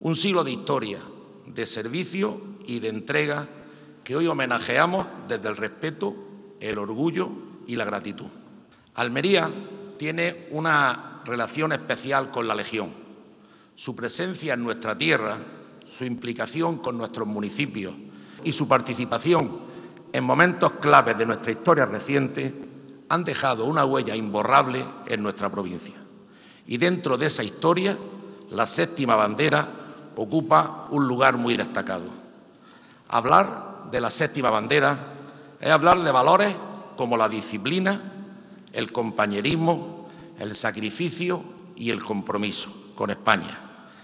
La exposición conmemorativa se ha inaugurado esta tarde en un multitudinario acto que ha congregado a una amplia representación de la sociedad almeriense en el Patio de Luces de Diputación.
24-04_legion_diputado.mp3